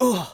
xys受伤4.wav 0:00.00 0:00.34 xys受伤4.wav WAV · 29 KB · 單聲道 (1ch) 下载文件 本站所有音效均采用 CC0 授权 ，可免费用于商业与个人项目，无需署名。